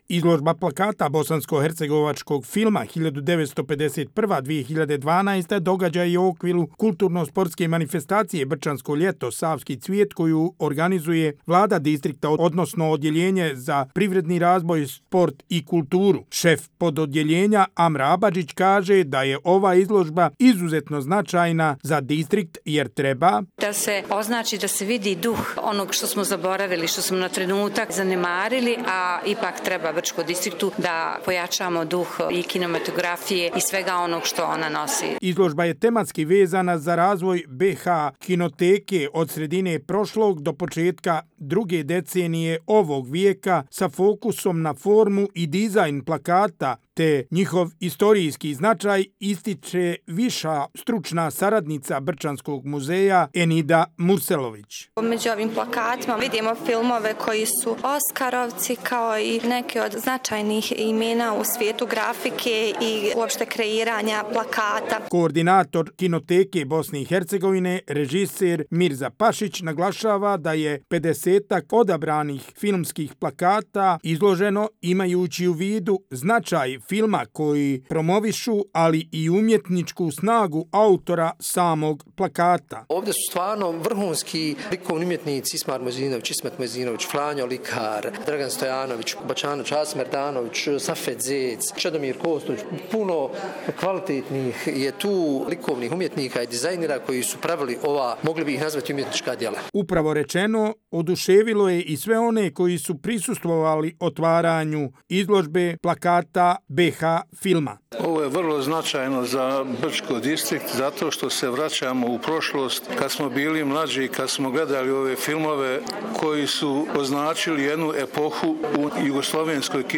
Izložba plakata bosanskohercegovačkog filma 1951–2012 svečano je otvorena danas u Muzeju Brčko distrikta BiH, u okviru kulturno-sportske manifestacije „Brčansko ljeto – Savski cvijet 2025”.